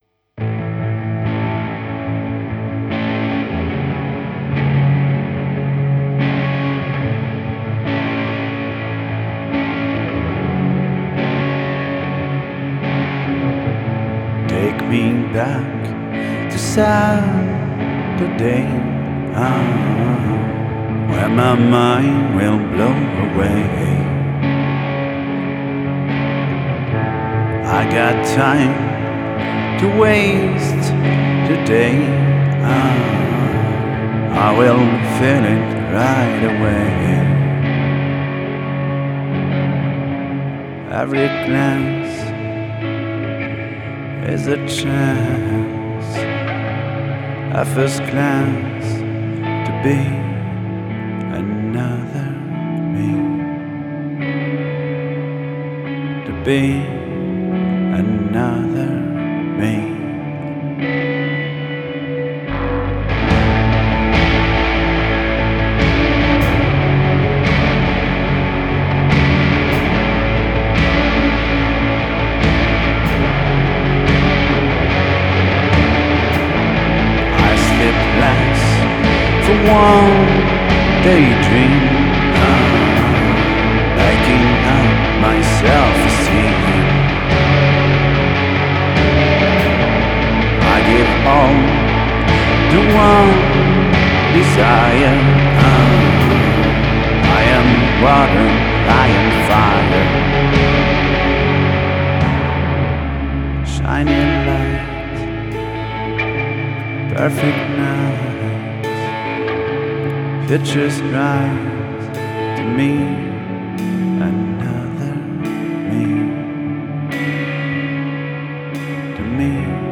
une folk teintée de shoegazing pleine de poésie